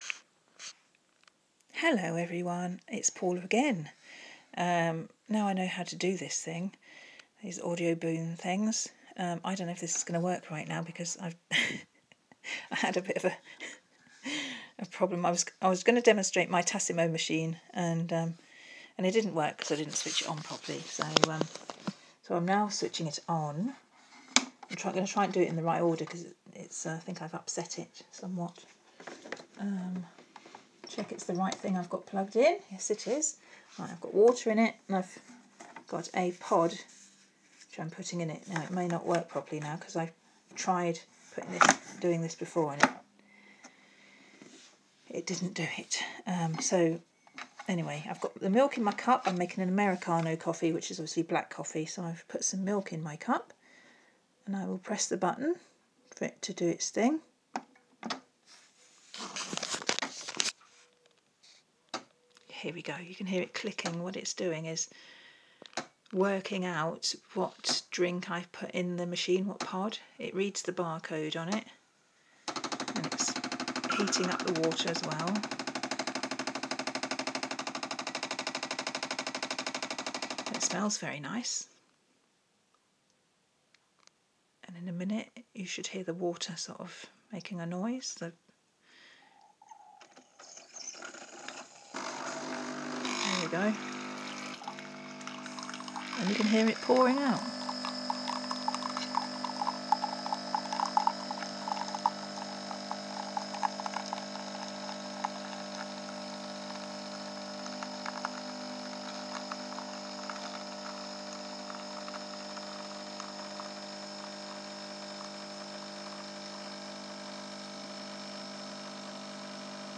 Demonstrating the Tassimo coffee machine I got for my birthday from my family.